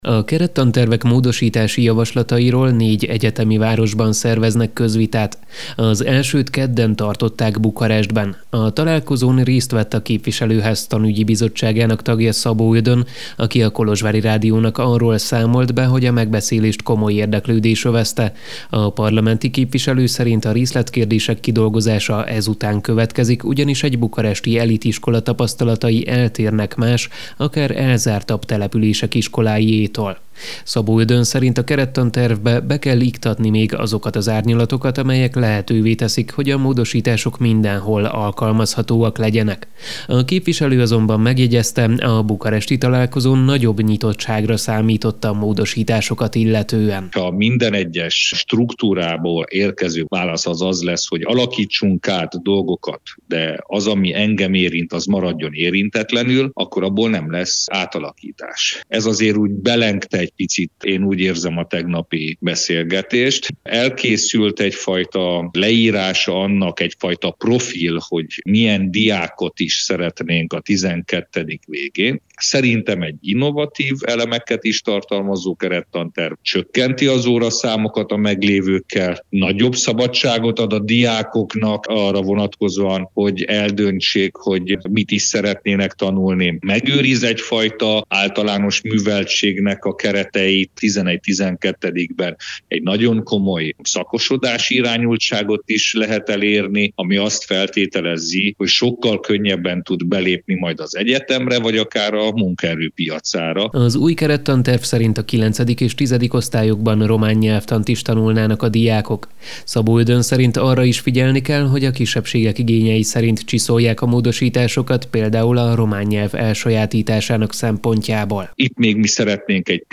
Konzultál a minisztérium és a szakszervezetek az új tantervekkel kapcsolatban. Igazgatót és oktatási szakpolitikust is megkérdeztünk.
A találkozón részt vett a képviselőház tanügyi bizottságának tagja Szabó Ödön, aki a Kolozsvári Rádiónak arról számolt be, hogy a megbeszélést komoly érdeklődés övezte.